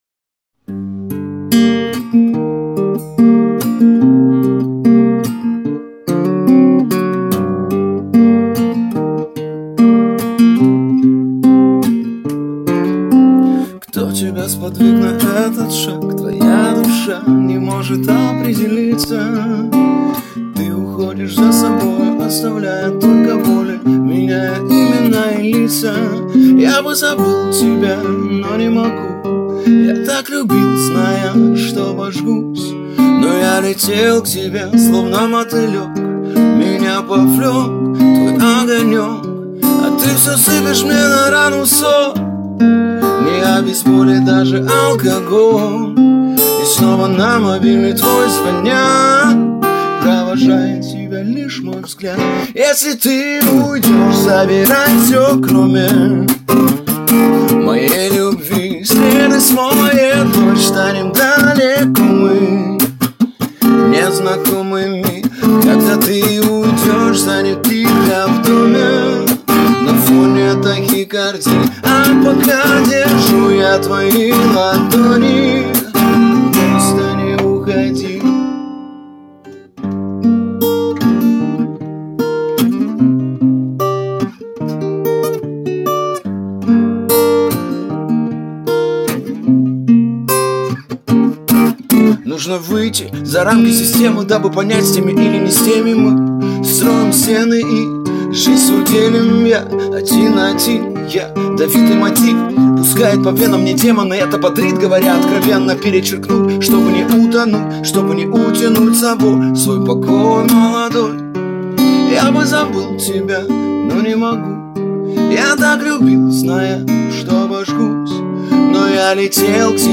Во вложении демо с диктофона - гитара, вокал.
(диктофон)